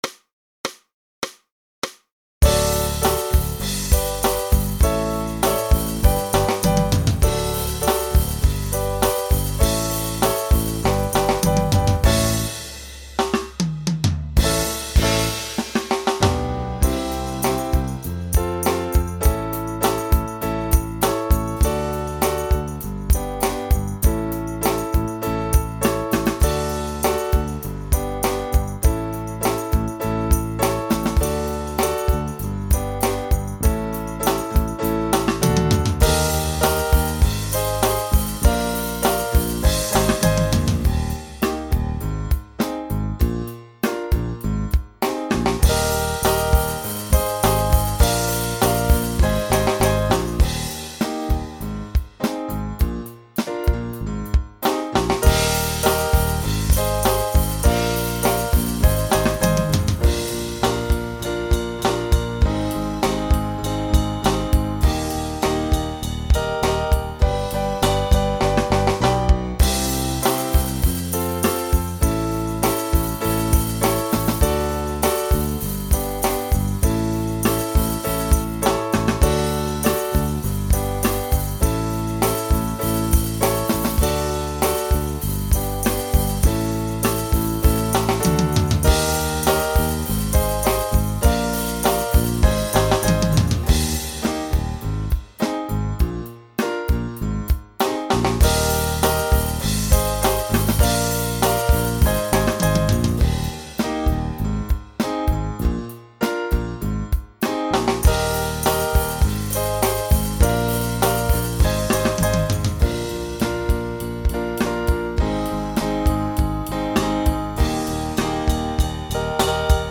Voicing TTB Instrumental piano Genre Rock
Mid-tempo